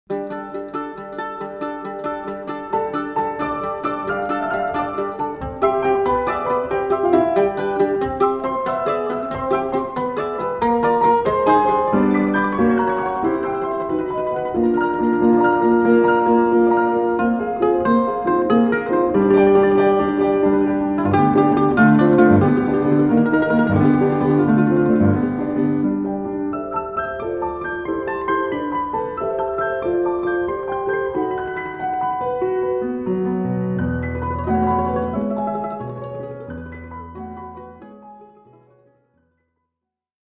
Para: Mandolina y piano
Nivel: Intermedio